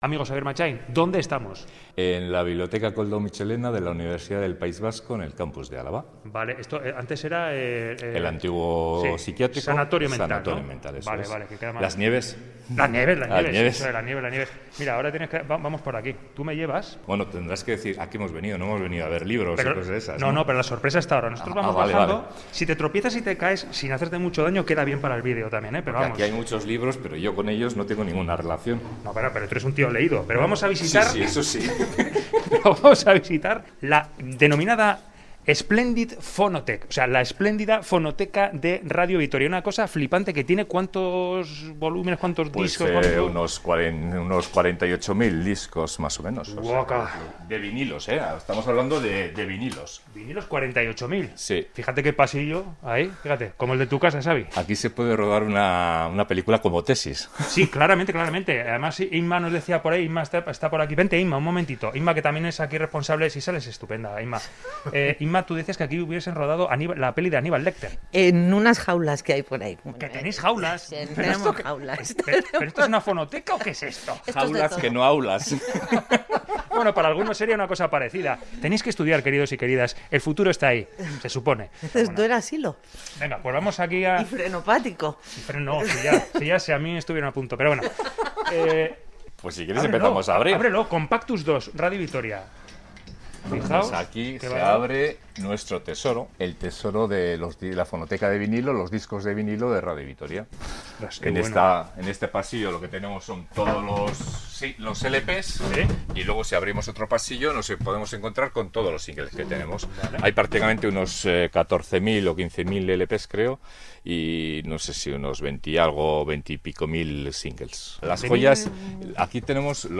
Audio: Reportaje:'Splendid Fonotek en Radio Vitoria'